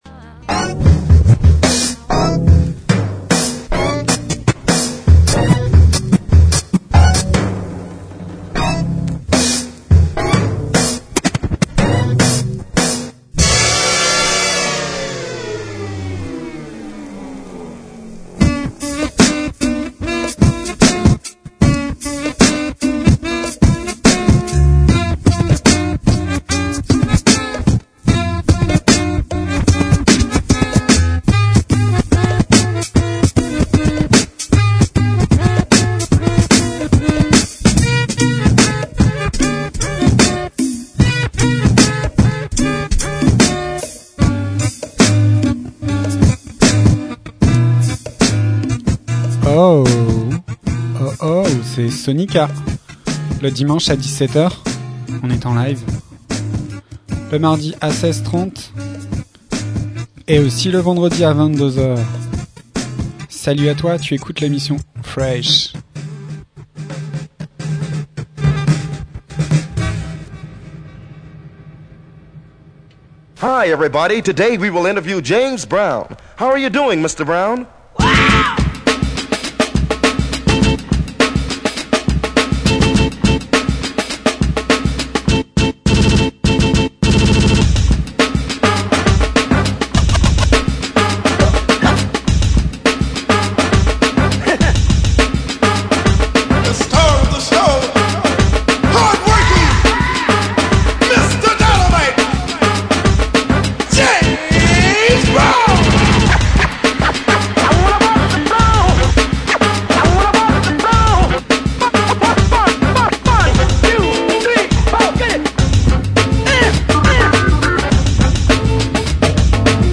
SoniKa en live sur RADIO PFM 99.9 le dimanche à 17h !!